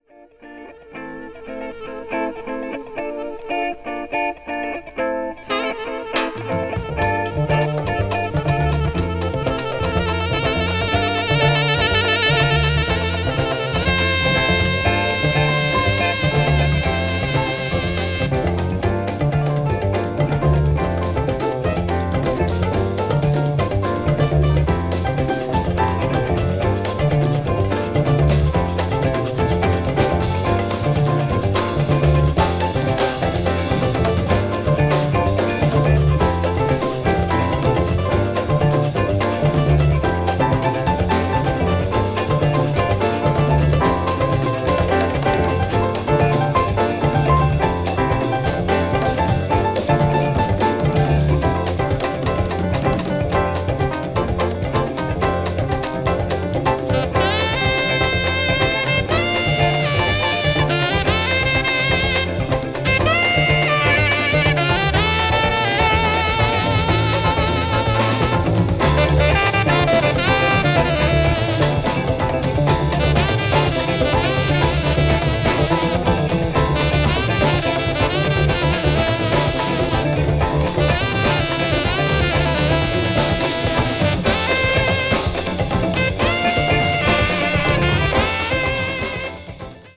緩急をつけながら竜巻のように上昇する展開が凄いA1/B1